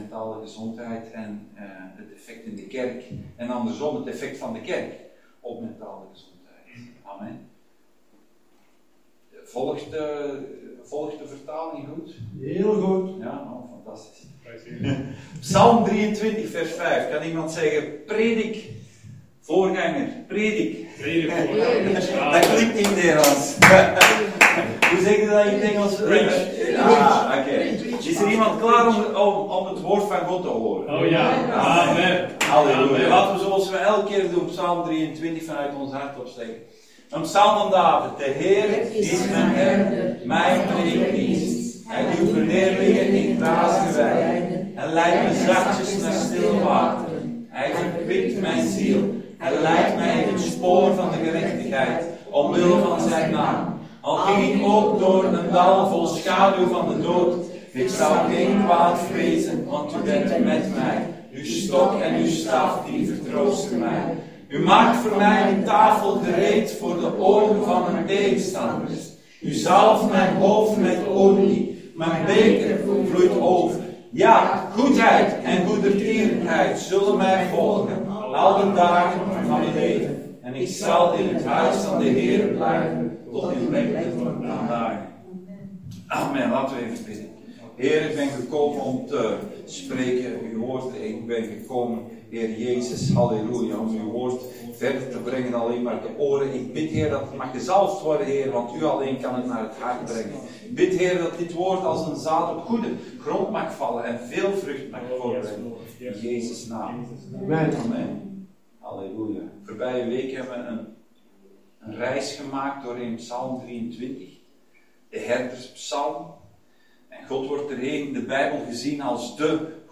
Dienstsoort: Zondag Dienst